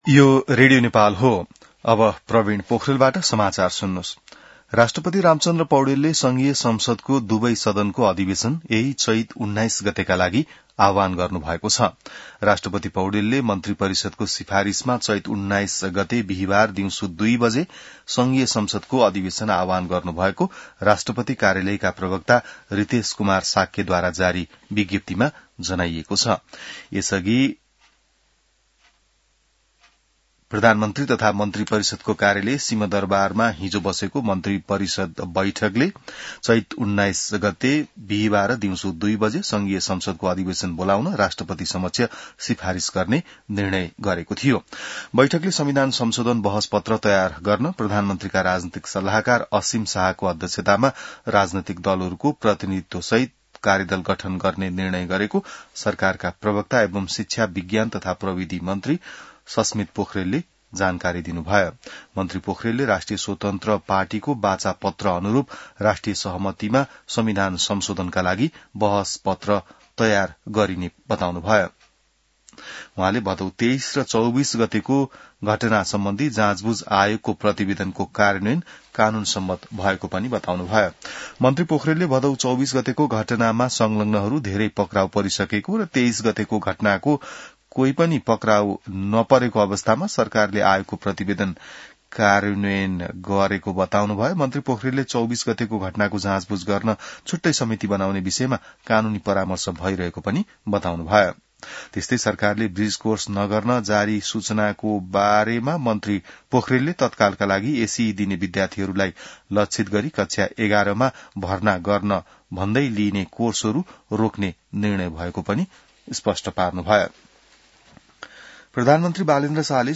बिहान ६ बजेको नेपाली समाचार : १७ चैत , २०८२